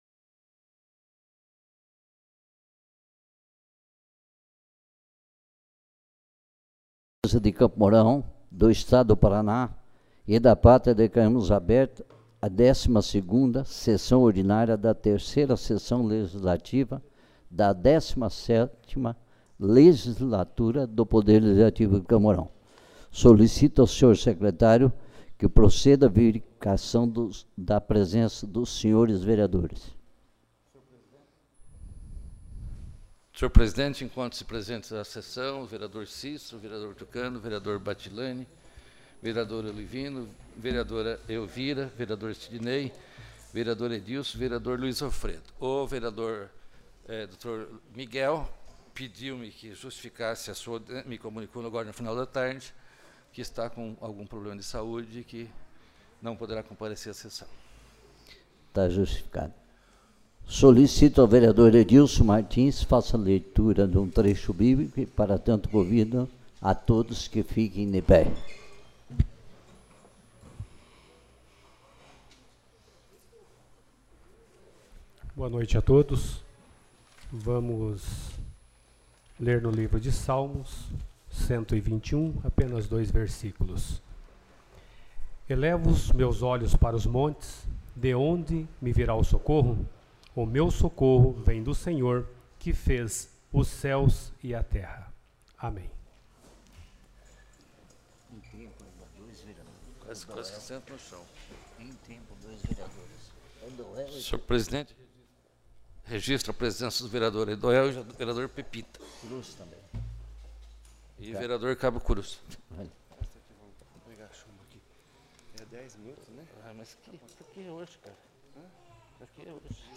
12ª Sessão Ordinária